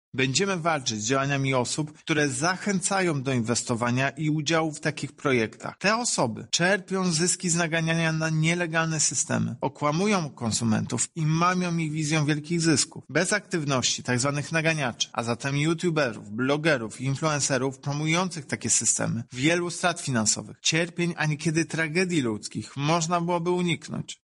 Systemy promocyjne typu piramida są bardzo groźną, nieuczciwą praktyką rynkową – tłumaczy prezes urzędu, Tomasz Chróstny: